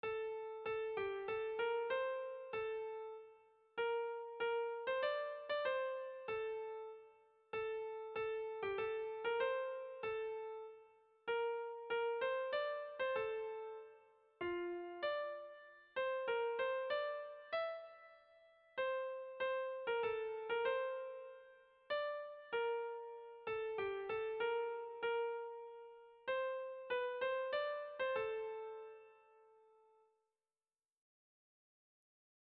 Irrizkoa
Eibar < Debabarrena < Gipuzkoa < Euskal Herria
Zortziko txikia (hg) / Lau puntuko txikia (ip)
A1A2BD